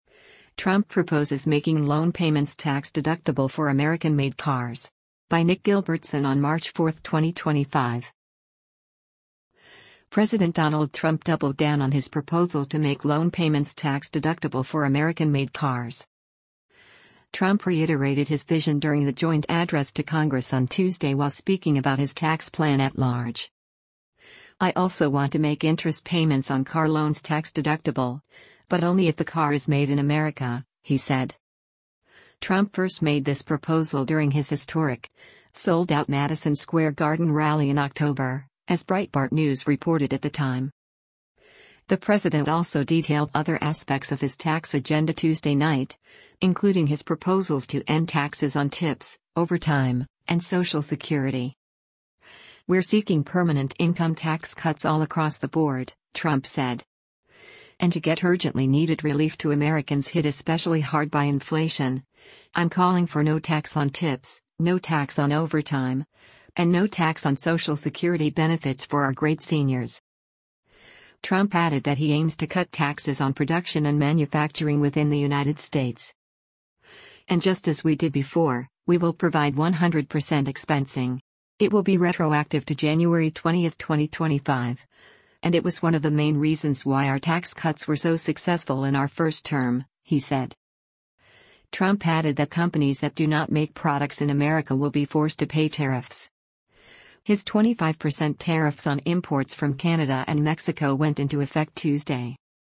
Trump reiterated his vision during the joint address to Congress on Tuesday while speaking about his tax plan at large.